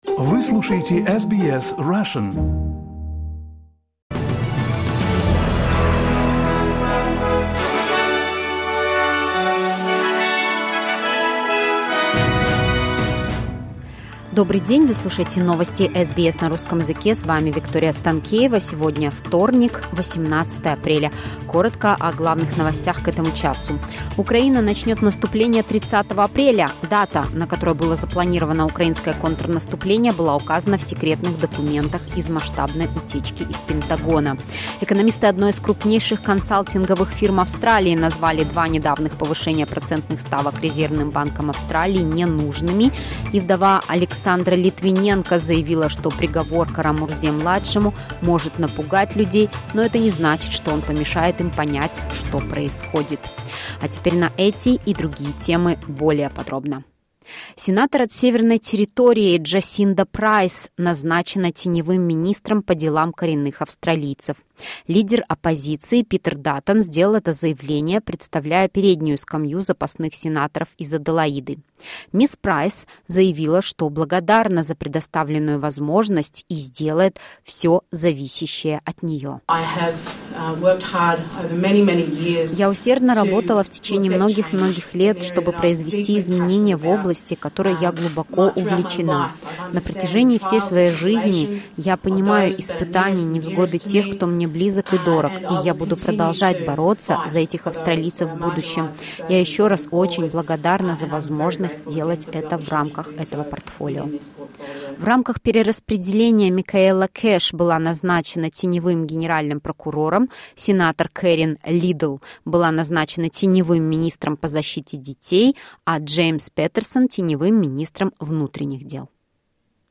SBS news in Russian — 18.04.2023